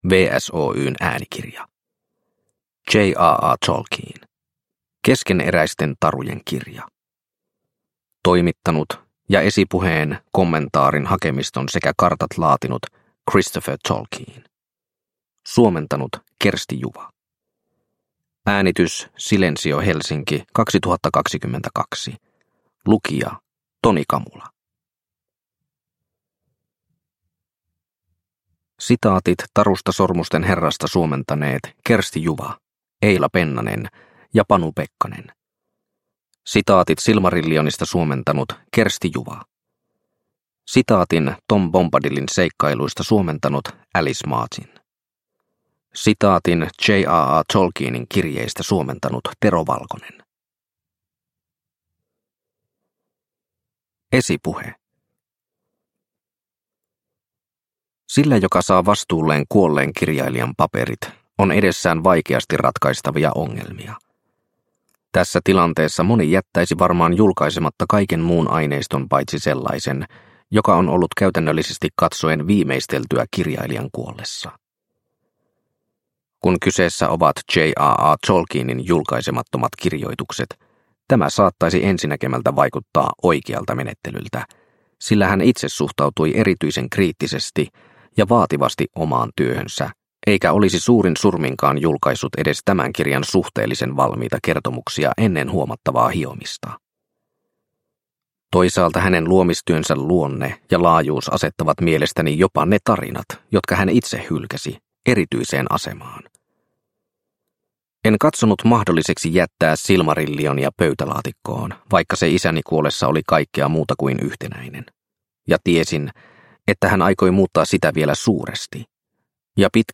Keskeneräisten tarujen kirja (ljudbok) av J. R. R. Tolkien